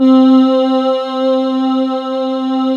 Index of /90_sSampleCDs/USB Soundscan vol.28 - Choir Acoustic & Synth [AKAI] 1CD/Partition D/14-AH VOXST